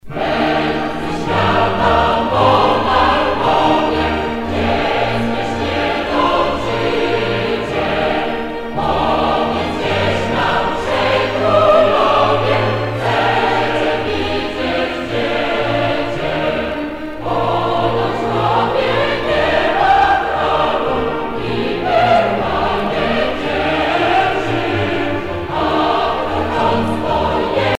Noël, Nativité
Pièce musicale éditée